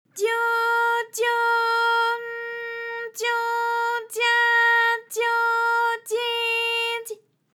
ALYS-DB-001-JPN - First Japanese UTAU vocal library of ALYS.
dyo_dyo_n_dyo_dya_dyo_dyi_dy.wav